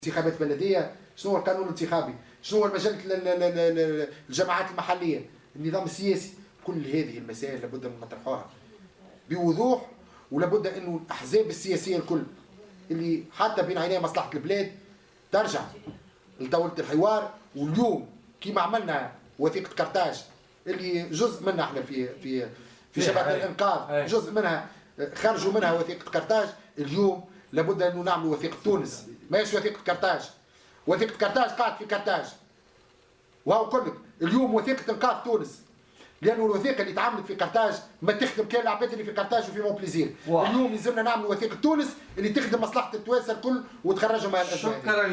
ودعا ضيف "بوليتيكا" إلى كتابة وثيقة انقاذ تونس معتبرا أن وثيقة قرطاج بقيت في قرطاج ولا تخدم الا الأطراف في قرطاج ومونبليزير، بحسب تعبيره.